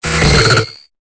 Cri de Loupio dans Pokémon Épée et Bouclier.